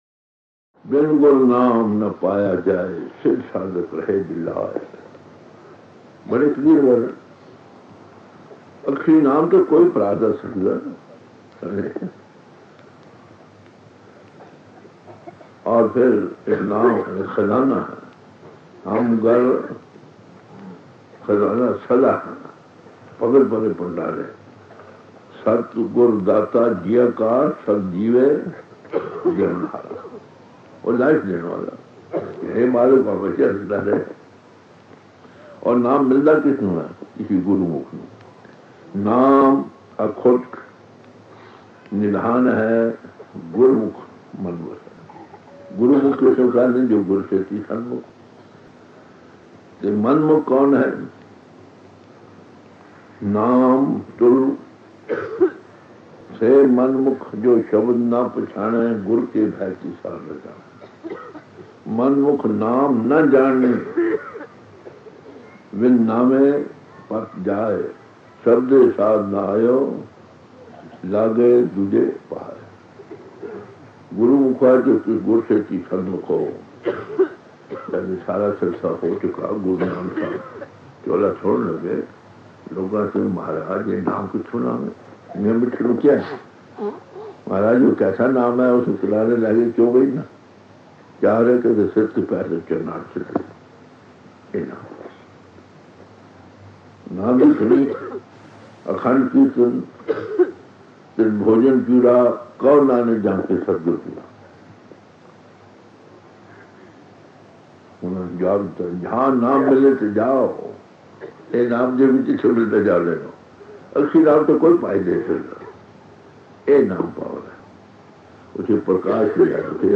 PUNJABI SATSANGS